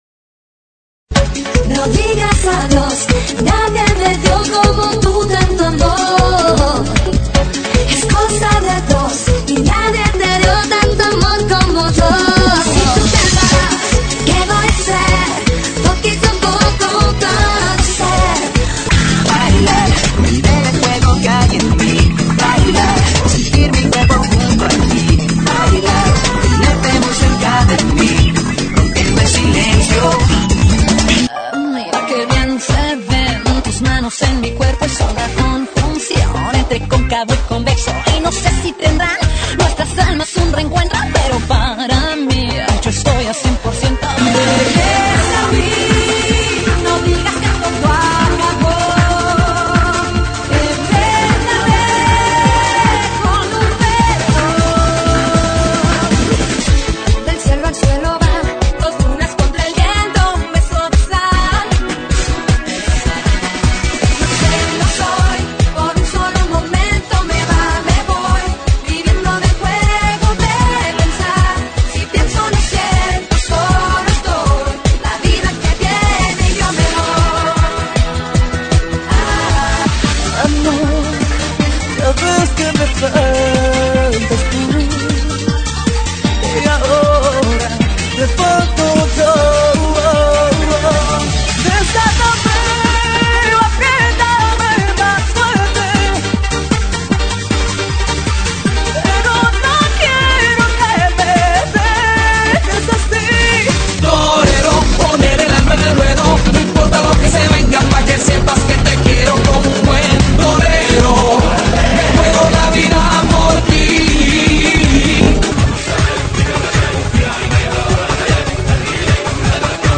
GENERO:RADIO
DANCE RETRO,